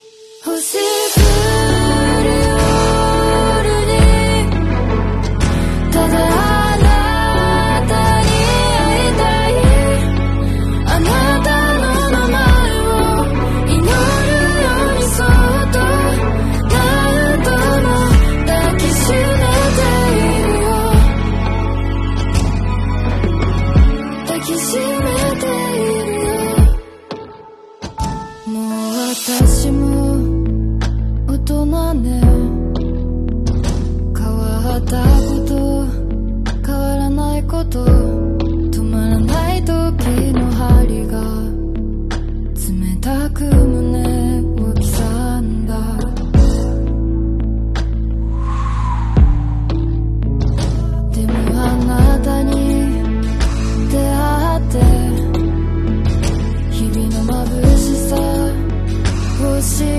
Lake Biwa Fireworks Festival 2025 sound effects free download
📍Shiga Prefecture